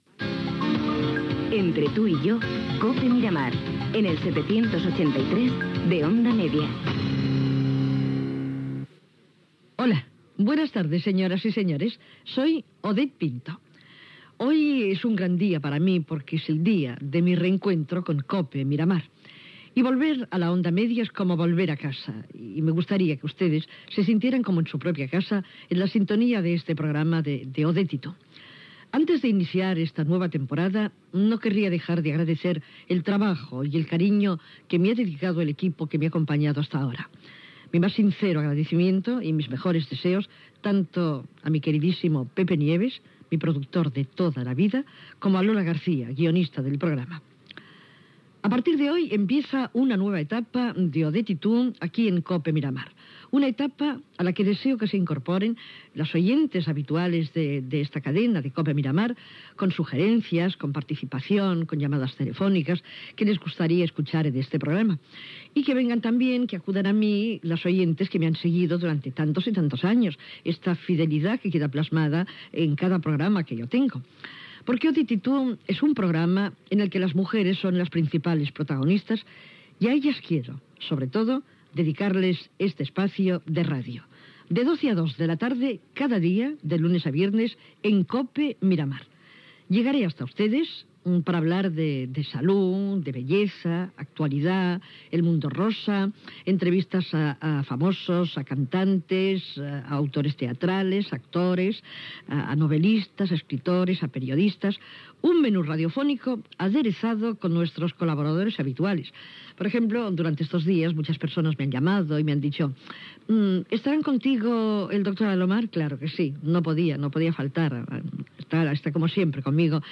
indicatiu cantat del programa
conversa amb els actors Paco Morán i Joan Pera que interpreten l'obra "La jaula de las locas" Gènere radiofònic Entreteniment